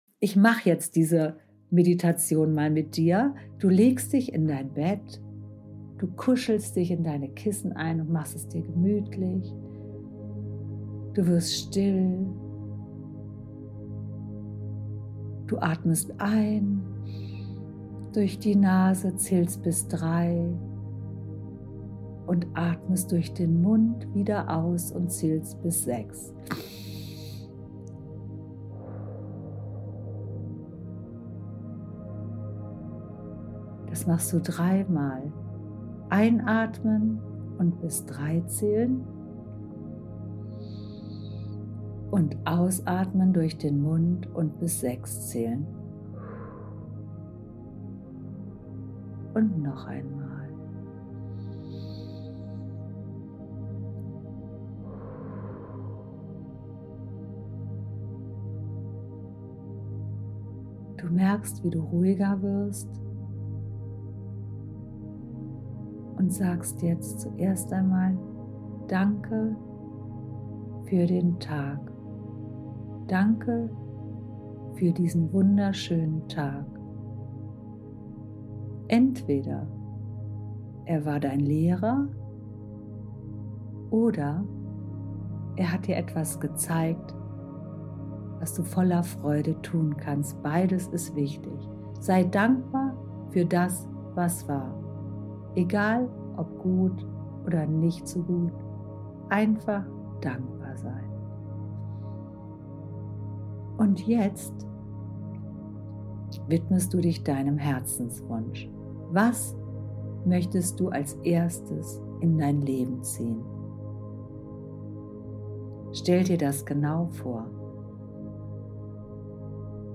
Manifestieren im Schlaf: Einschlafmeditation